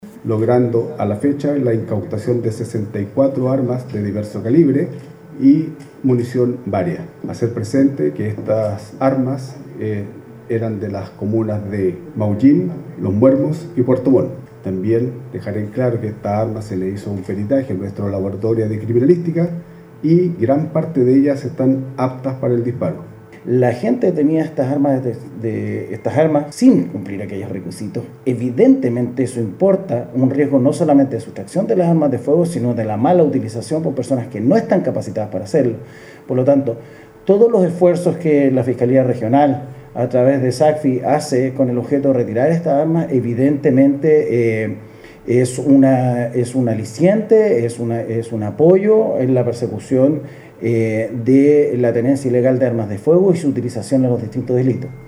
Se trata de 26 revólveres, 21 escopetas, 13 rifles y 4 pistolas, las cuales pertenecían a personas fallecidas con domicilio en las comunas de Los Muermos, Maullín y Puerto Montt. Si bien estaban en manos de familiares, éstas no habían sido regularizadas, como lo manifestó el prefecto Andrés Canelo, jefe de la prefectura provincial de la PDI y el fiscal Rodrigo Oyarzún, fiscal a cargo de la SACFI Los Lagos.